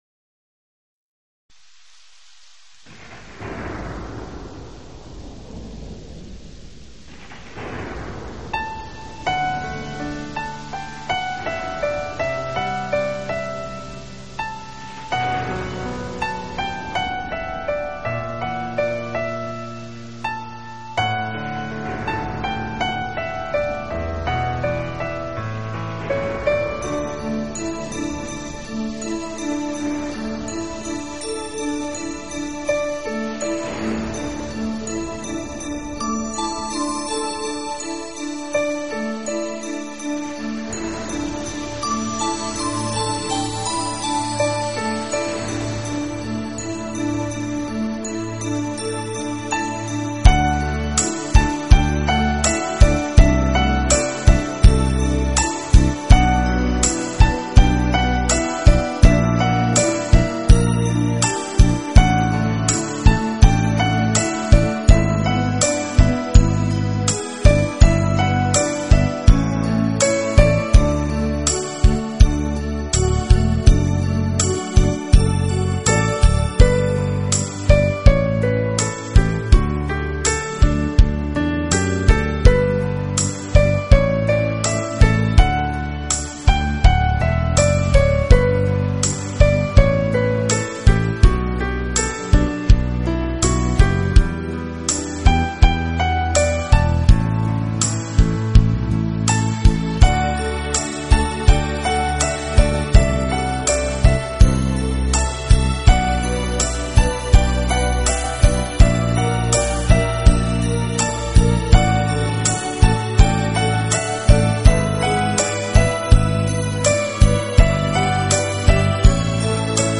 音乐类型: New Age
MP3/320K NEW AGE是种宁静、安逸、闲息的音乐，纯音乐作品占的比重较多，有歌唱的占较少。